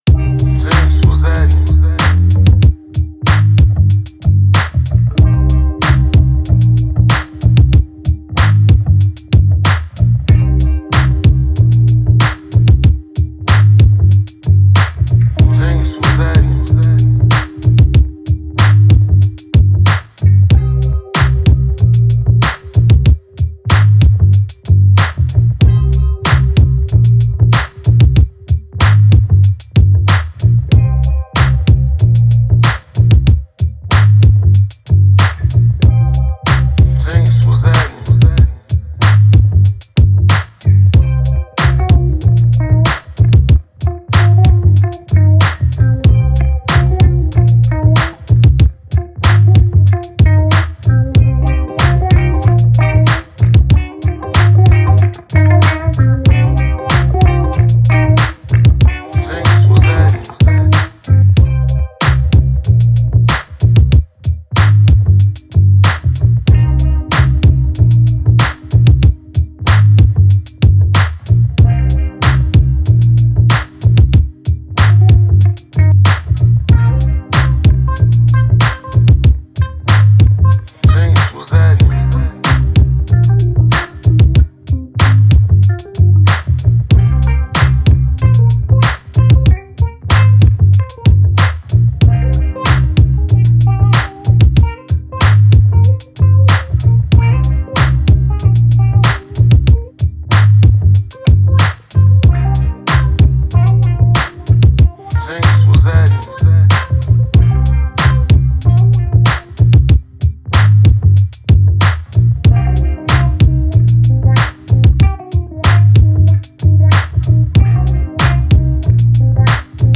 All samples produced in immaculate 24-Bit quality.